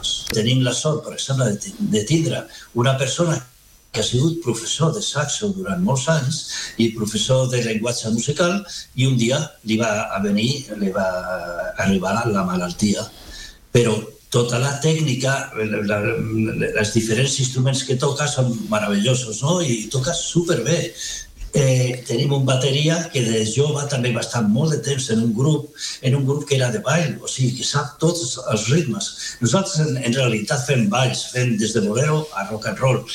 Aquest dijous hem conversat amb ell a l’Entrevista del dia del matinal de RCT, on ha recordat els orígens del projecte i una experiència especialment colpidora que exemplifica el poder de la música.